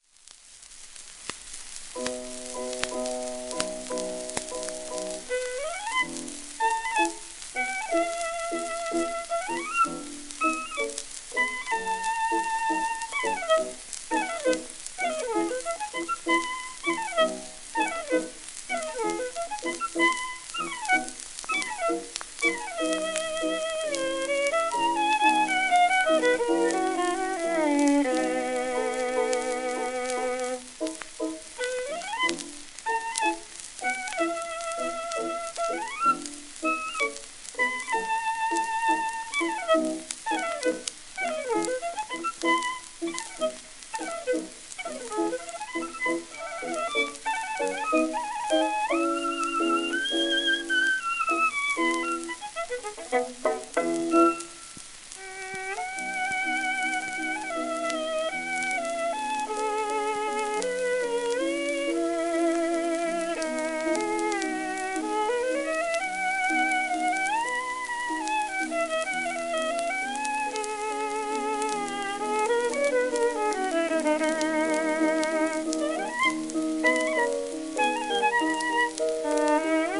セシリア・ハンセン(Vn:1898-1989)
Victrola 6447 (旧
盤質A-/B+ *キズ(ロンディーノ面に細かな針キズ)、薄いスレ
1924年録音
ロシア出身の女流ヴァイオリニスト。
旧 旧吹込みの略、電気録音以前の機械式録音盤（ラッパ吹込み）